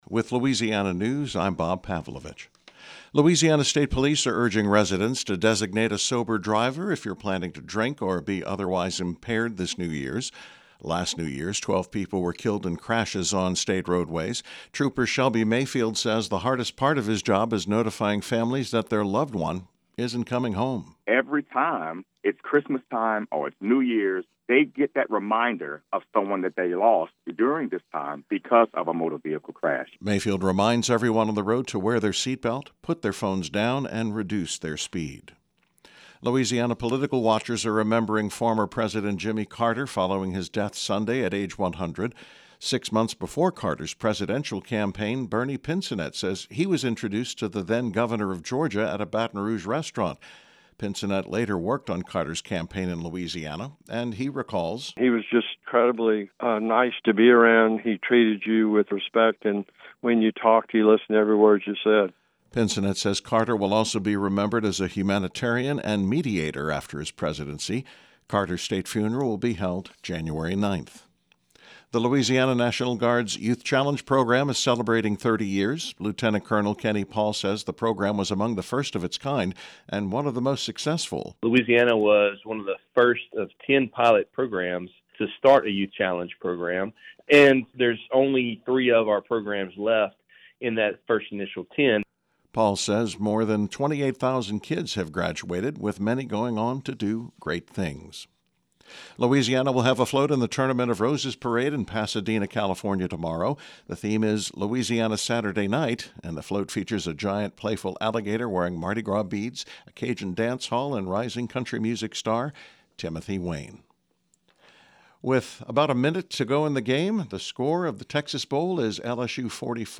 newscast.mp3